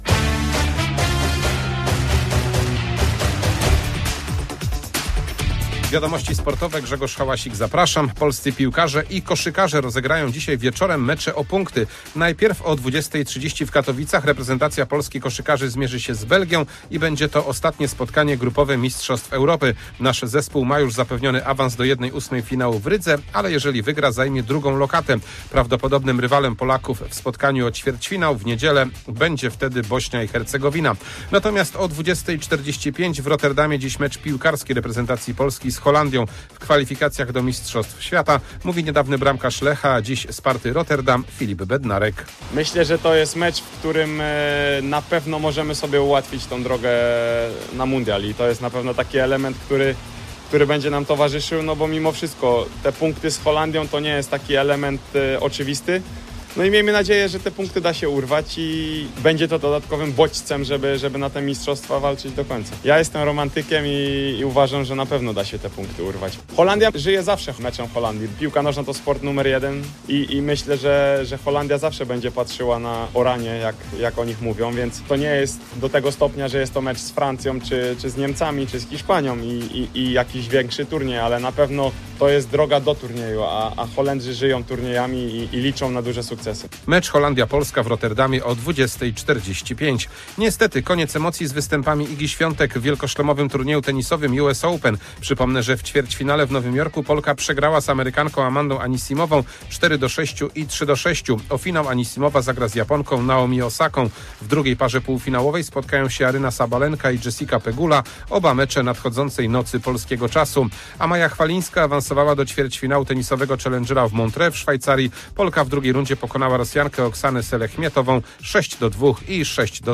04.09.2025 SERWIS SPORTOWY GODZ. 19:05